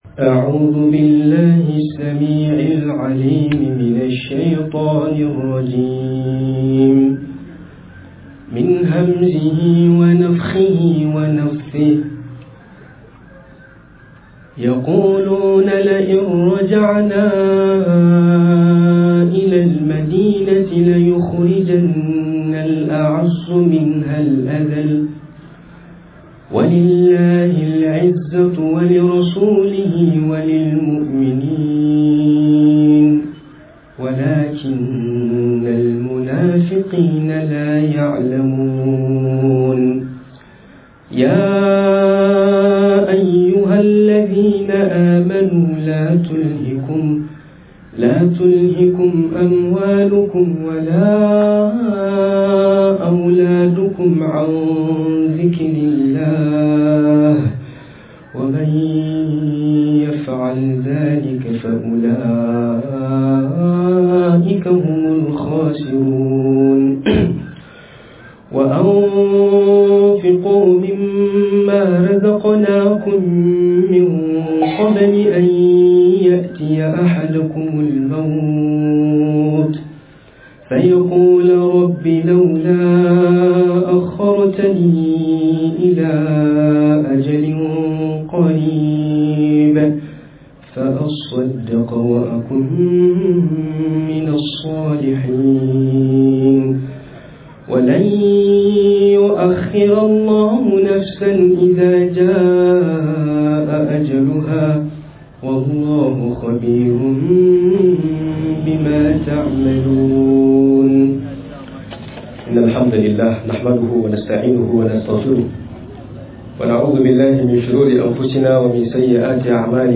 Nasihohi Game da tarbiyyar yara Acikin karin magana - MUHADARA